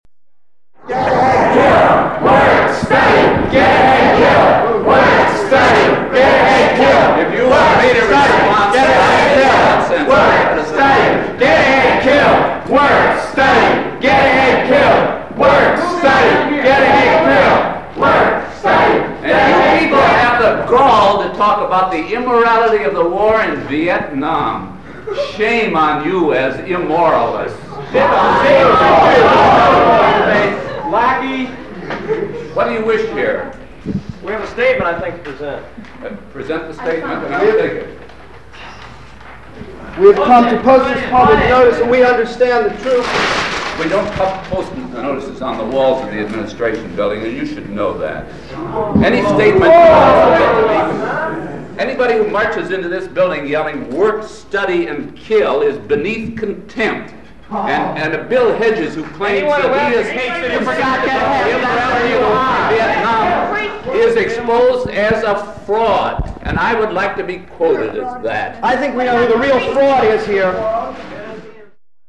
Inside the central hall of the Administration Building, up the staircase toward the second floor, the chant echoed louder off the walls.
Part of the WOBC recording, February 20, 1969.